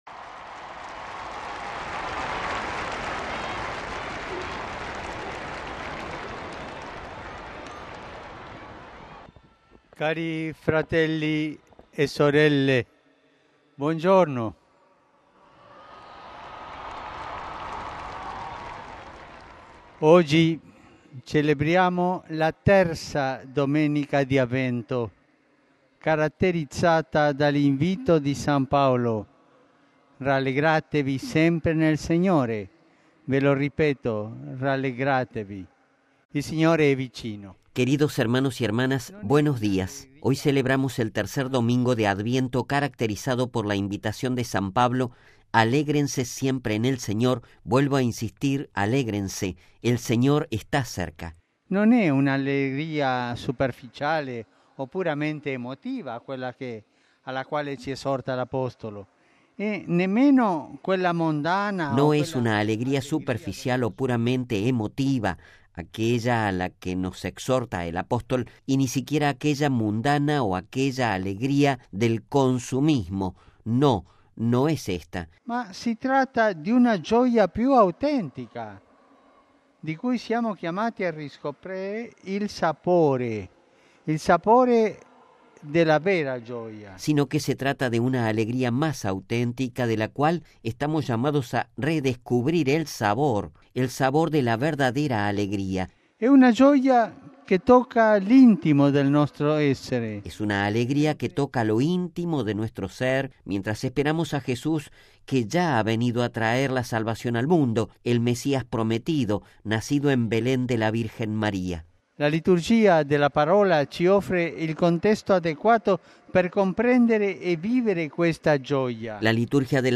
Voz y Texto completo de la reflexión del Papa, previa a la oración del Ángelus: